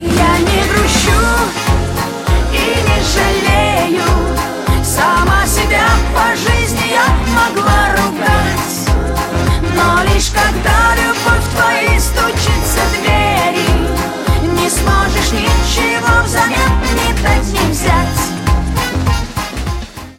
эстрада